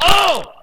player_hit.ogg